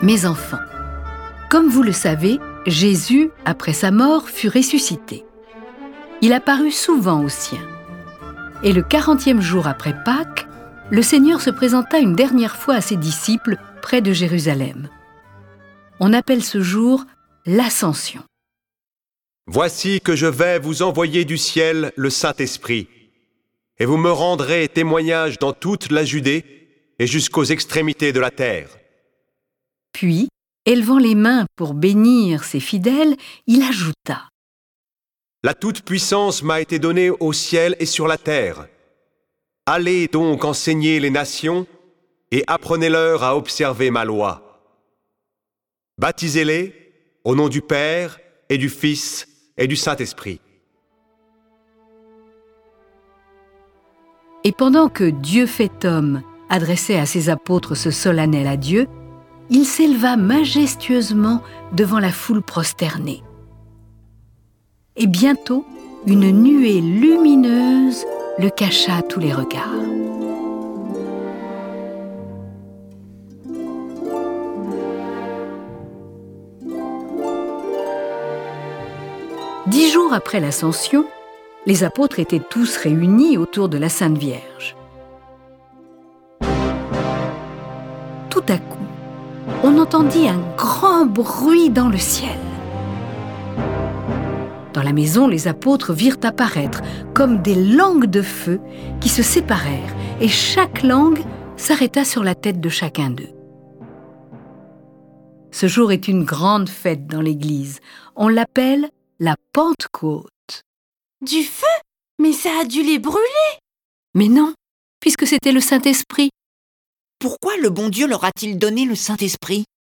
Cette version sonore de l'oeuvre de la comtesse de Ségur est animée par dix voix et accompagnée de près de quarante morceaux de musique classiques.
Le récit et les dialogues sont illustrés avec les musiques de Bach, Beethoven, Bizet, Corelli, Debussy, Dvorak, Locatelli, Mahler, Marcello, Mendelssohn, Mozart, Pergolèse, Schubert, Telemann, Vivaldi, Wagner.